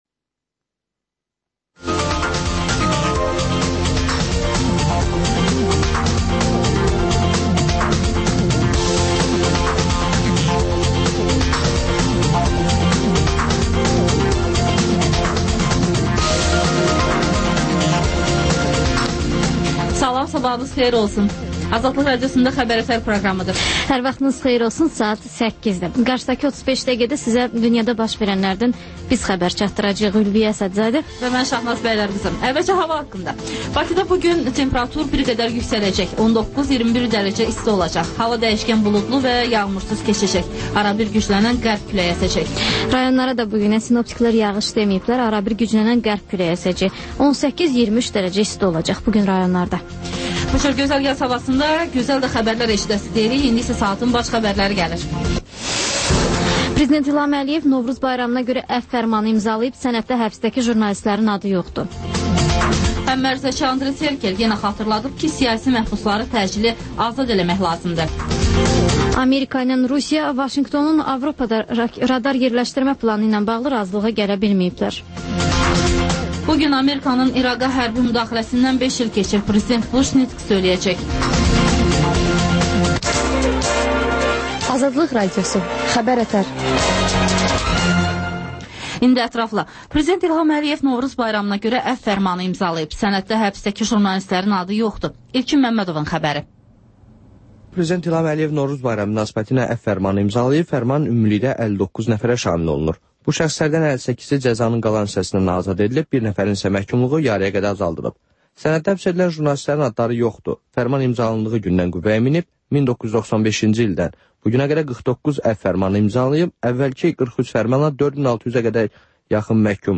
Xəbər-ətər: xəbərlər, müsahibələr və ŞƏFFAFLIQ: Korrupsiya haqqında xüsusi veriliş